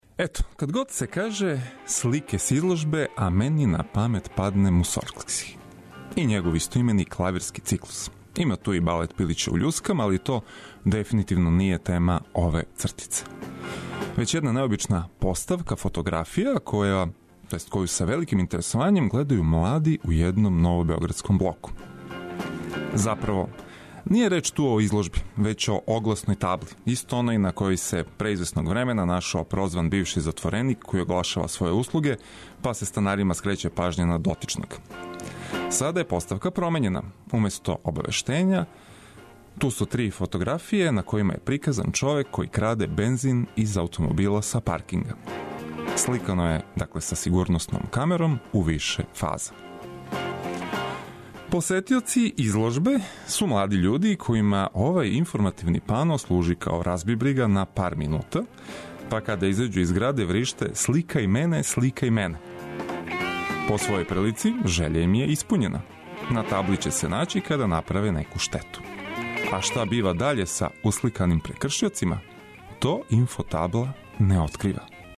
Нека дан почне уз добру музику коју ћемо прошарати информацијама од којих ћете имати користи.